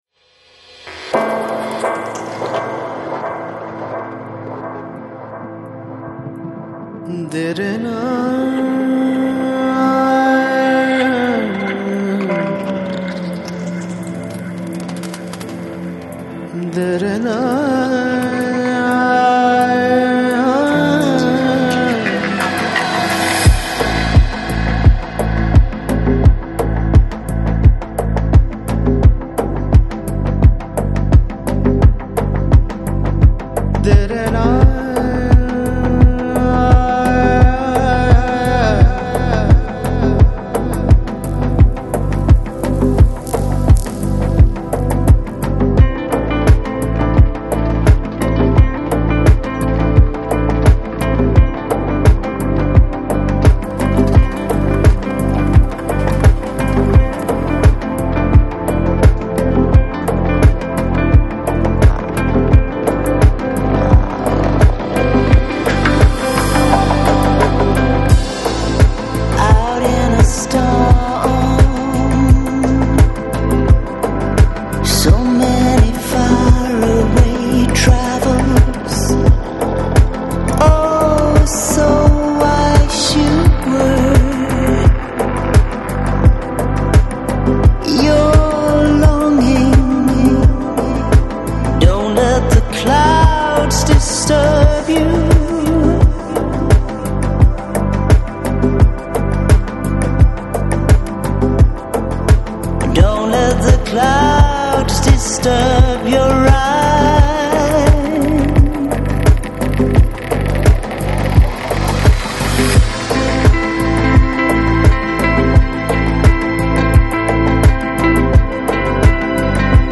Lounge, Chill Out, Balearic, Downtempo Год издания